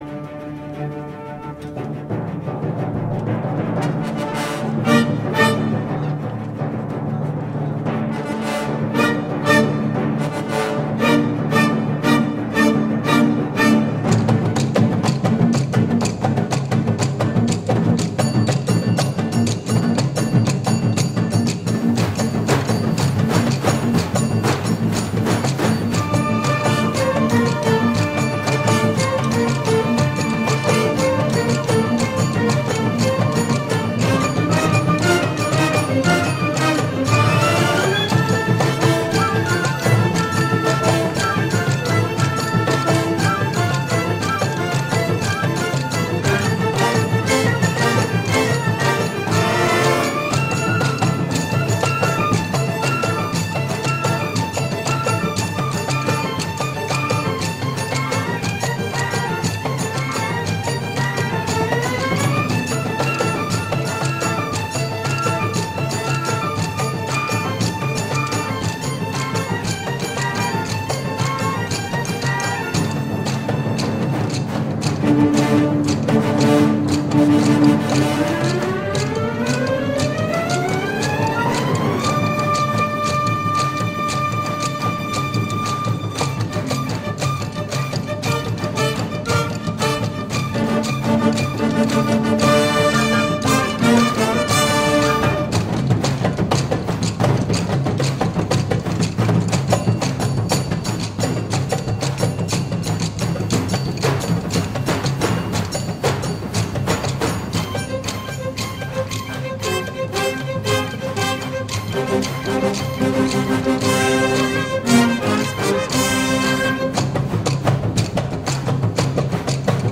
اجرای زنده
تالار وحدت آهنگساز و رهبر ارکستر